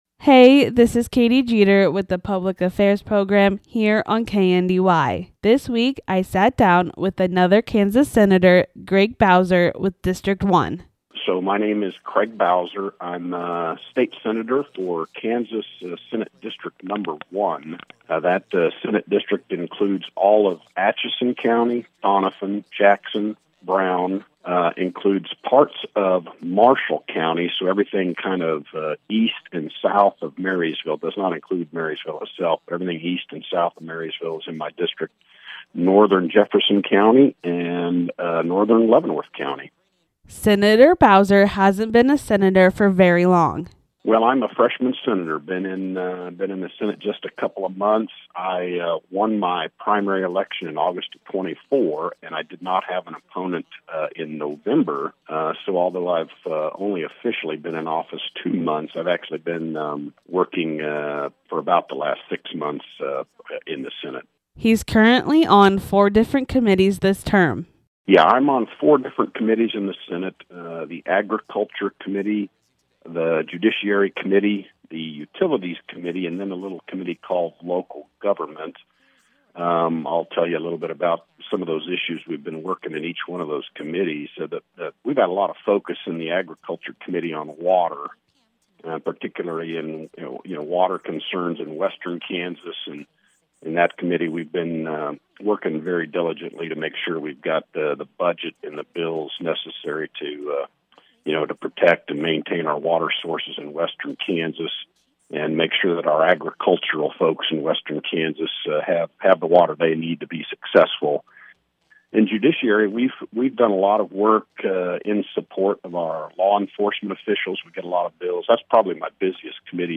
engaged in a conversation covering the counties within his district, his committee work, current legislative matters, and his time in office.